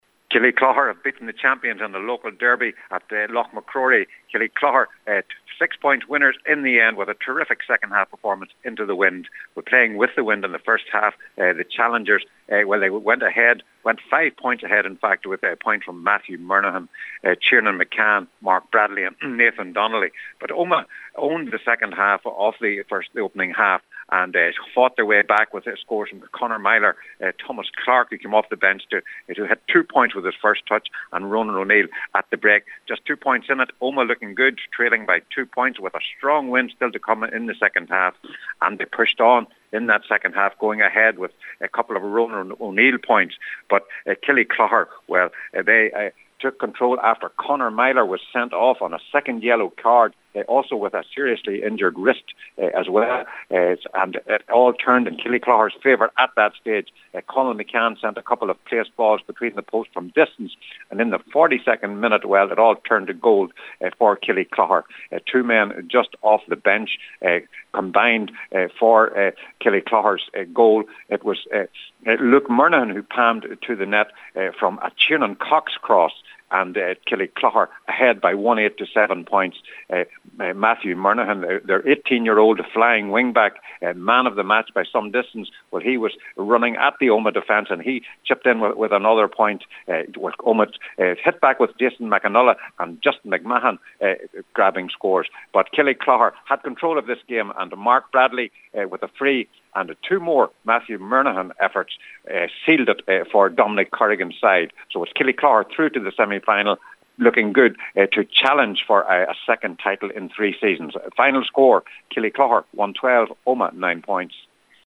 KIllyclogher beat Omagh St. Enda’s in Tyrone SFC – FT Report